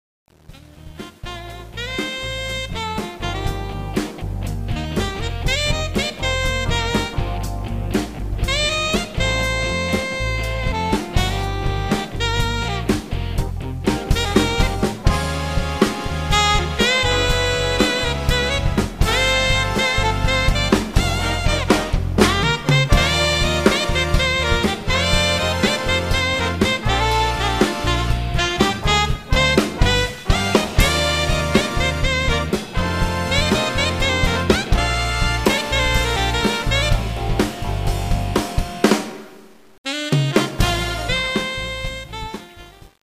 Recorded at Sanctuary Studios, Broadalbin, NY 2004-2011